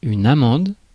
Ääntäminen
Synonyymit vulve Ääntäminen France: IPA: /a.mɑ̃d/ Haettu sana löytyi näillä lähdekielillä: ranska Käännös Ääninäyte Substantiivit 1. almond US Suku: f .